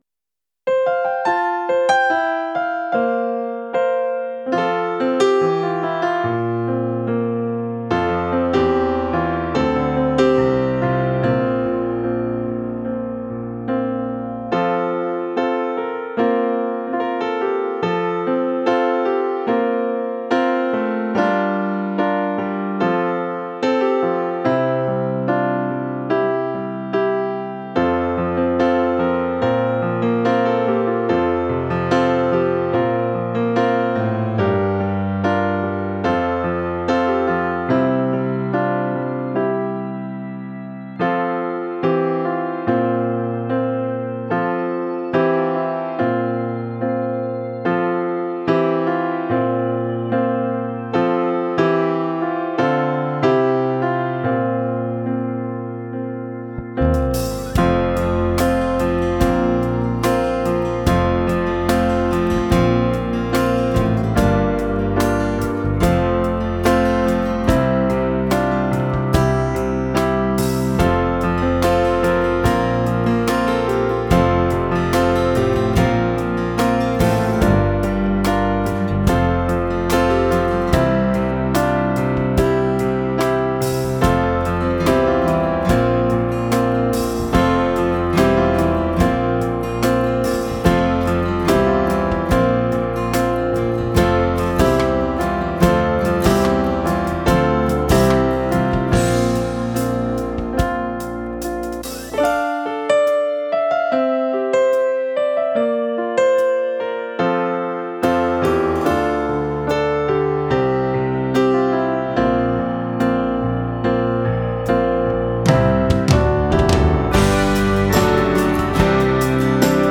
Drums
Basgitaar